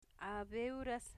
Phonological Representation a'beuɾas